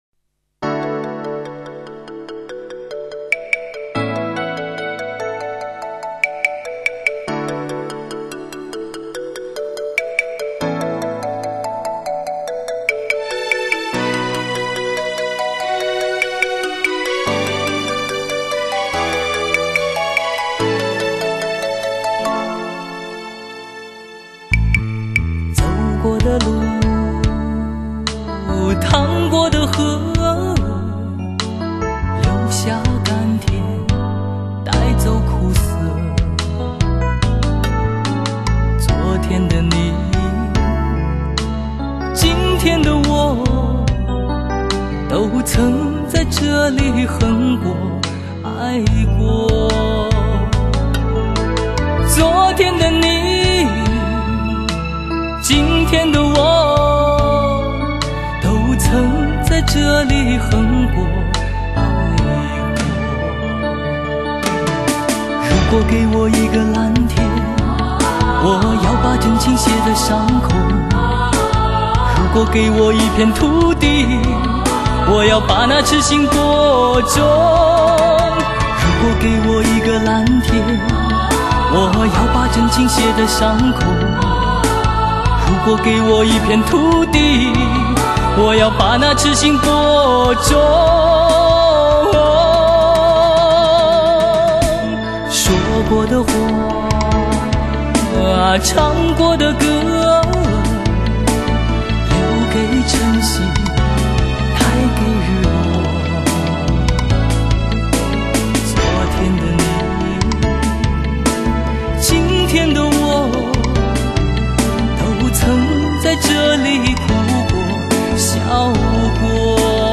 片尾曲